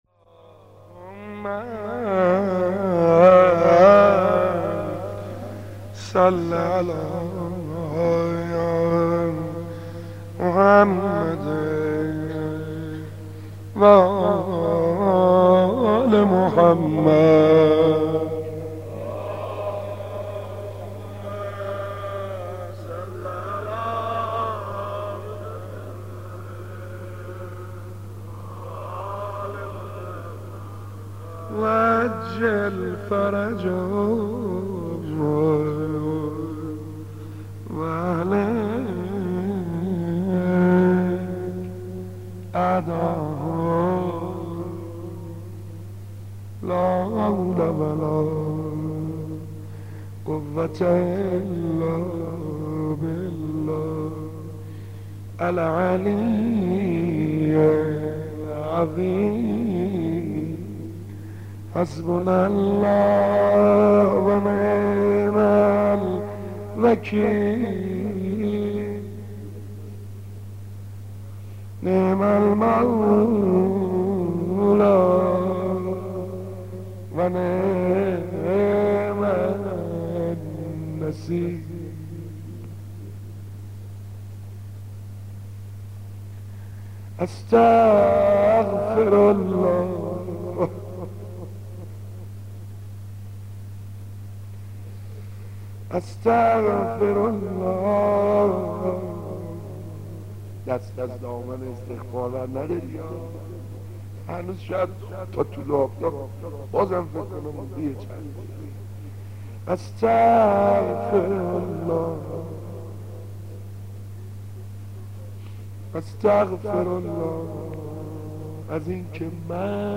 سعید حدادیان مداح
مناسبت : شب هشتم محرم
مداح : سعید حدادیان